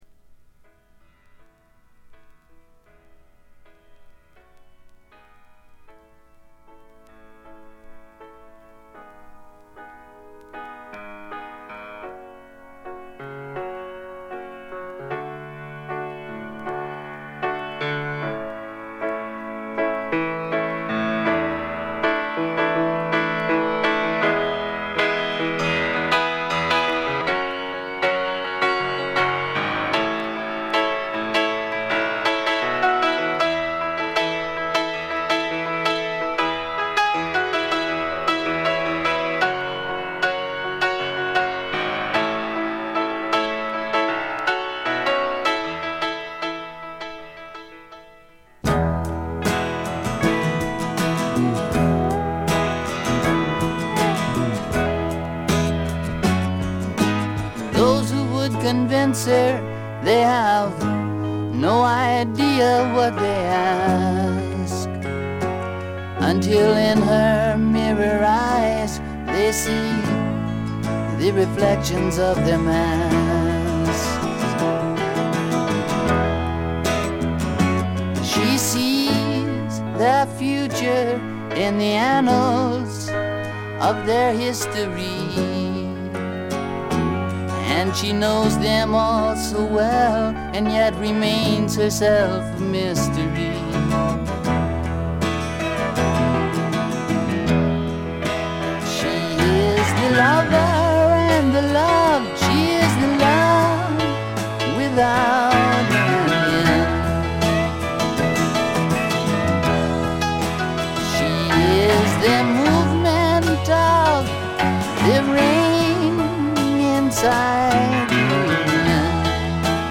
スワンプ路線とフォーキー路線が半々でどちらも素晴らしい出来ばえです。
試聴曲は現品からの取り込み音源です。
Vocals, Acoustic Guitar